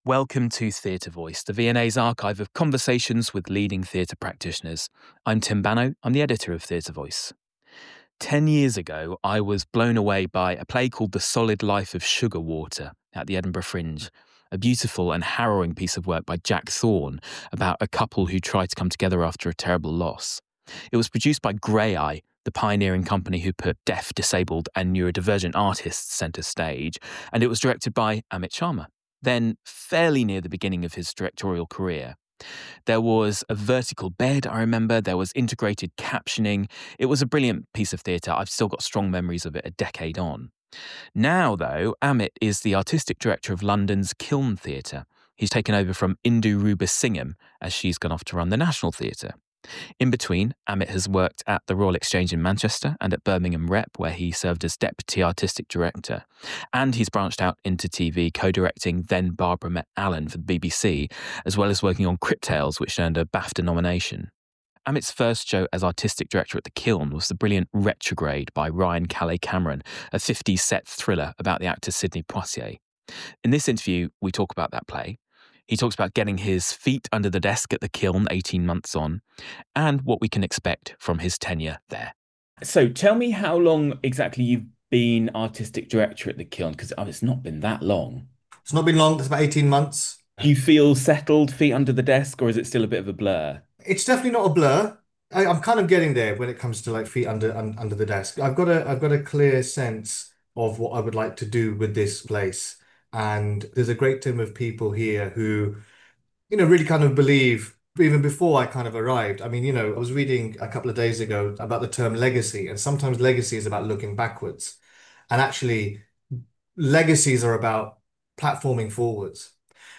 INTERVIEW
Recorded on Zoom, 12th June, 2025.